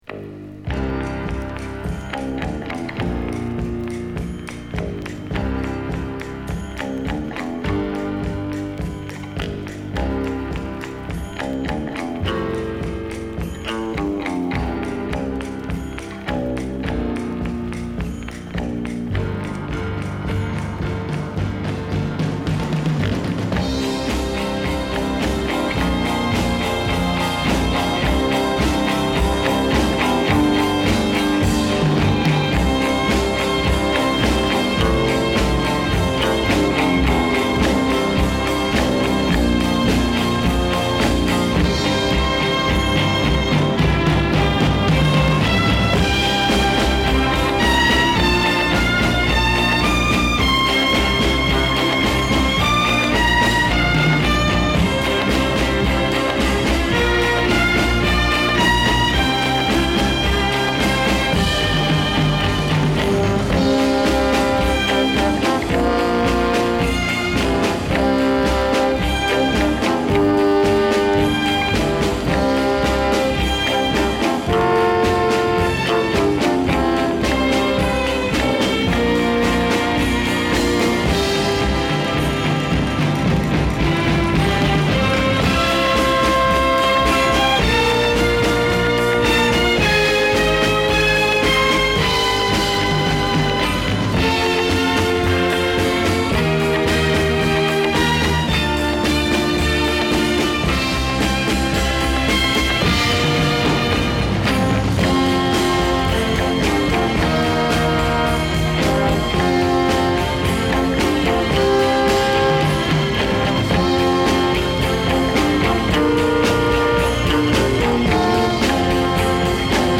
Ambient Avant-Garde Electronic Psych